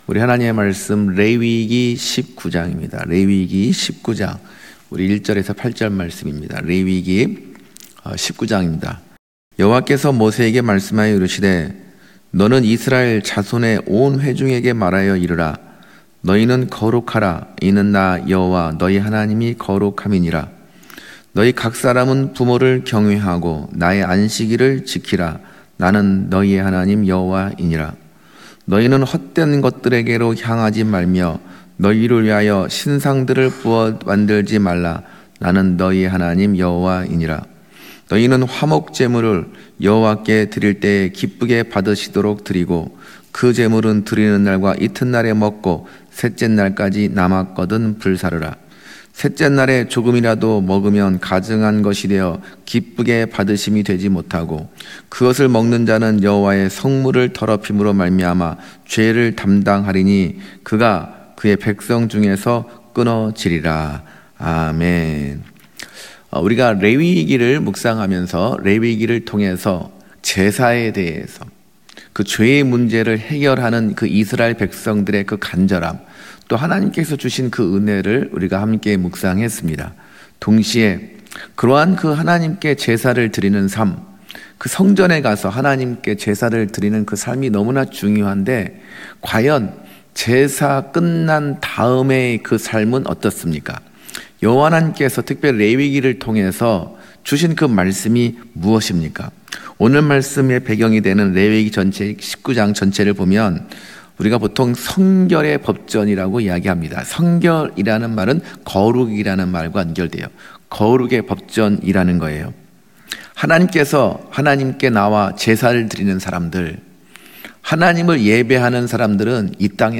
금요설교